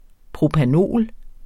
Udtale [ pʁopaˈnoˀl ]